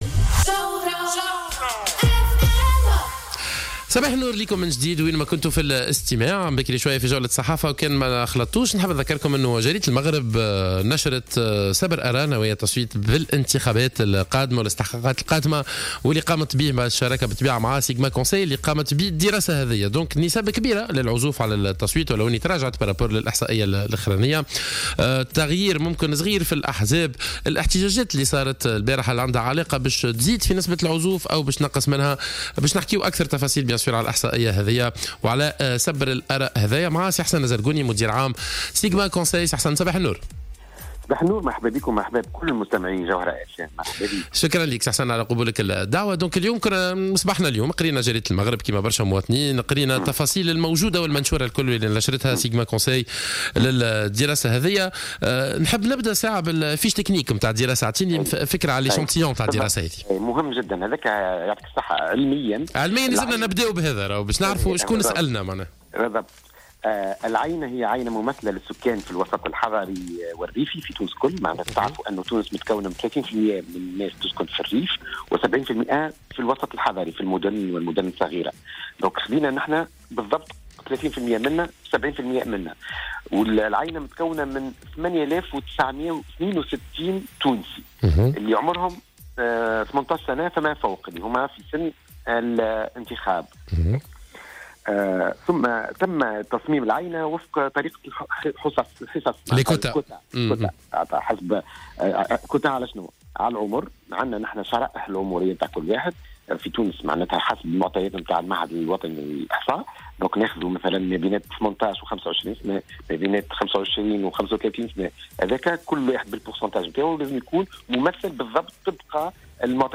وأوضح في اتصال هاتفي مع "الجوهرة اف أم" في برنامج "صباح الورد" ان الاستطلاع تم انجازه في الفترة الفاصلة بين 1 ديسمبر 2017 وأول أيام شهر جانفي 2018.